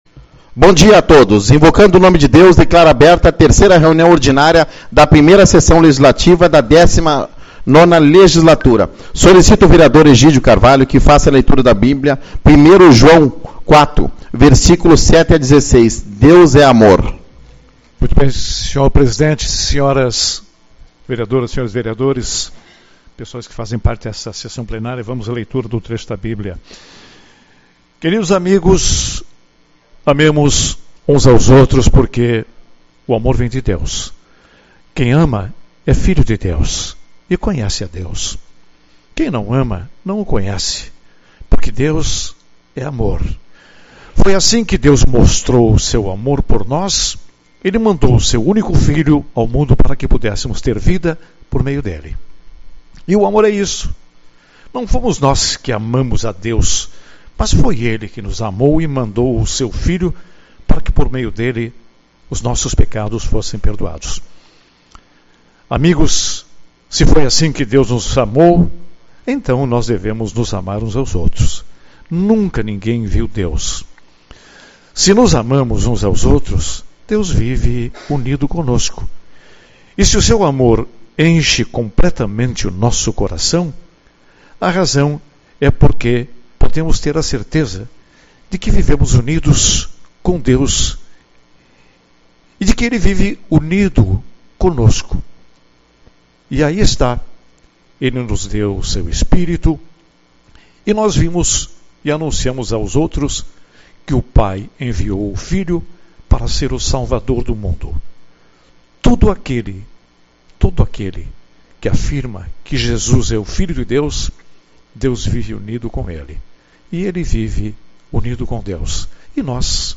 11/02 - Reunião Ordinária